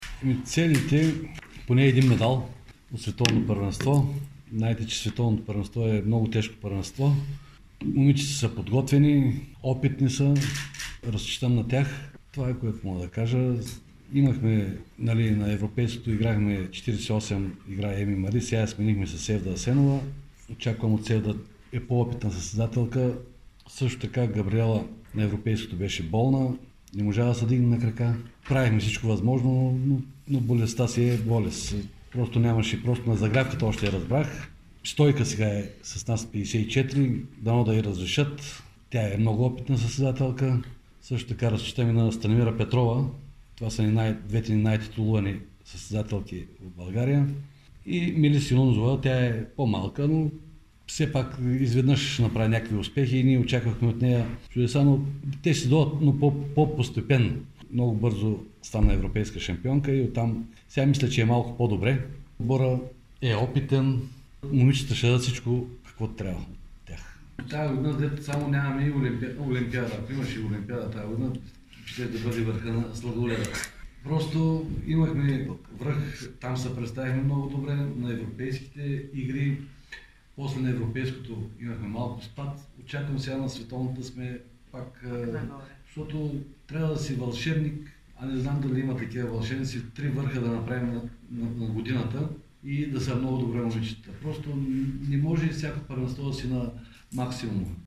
Това стана ясно на брифинг днес.